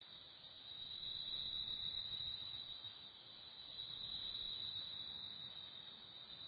jungleNight02.wav